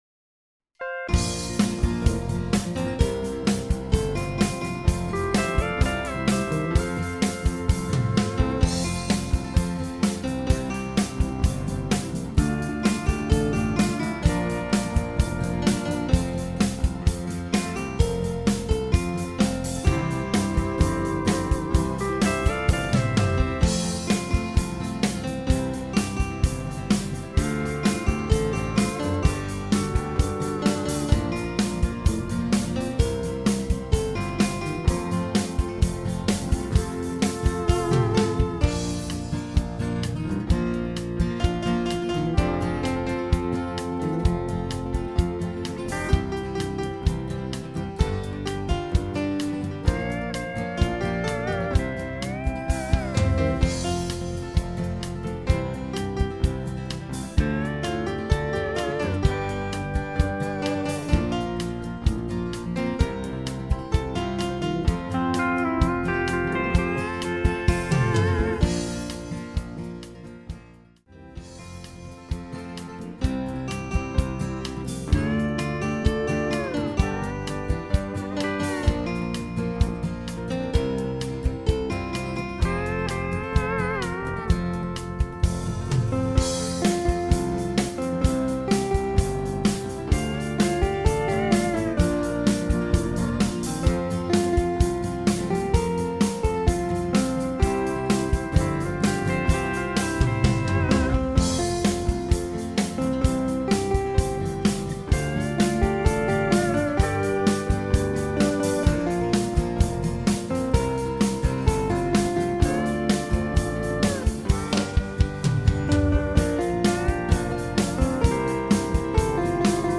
Sing Along